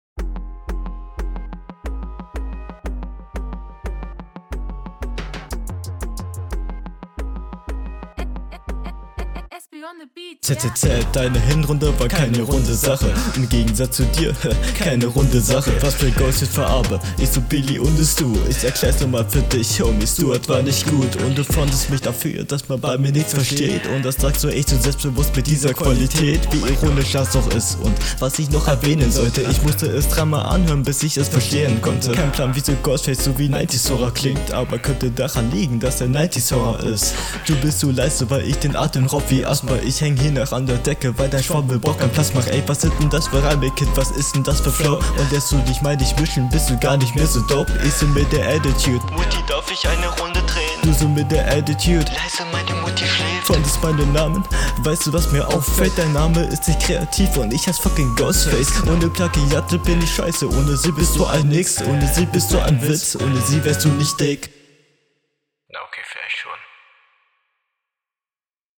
Flow: Wirkt eher off beat am anfang ab und zu aber bessert sich gegen ende …
Flow: Deine Stimme klingt stark nach Allergie oder Schnupfen.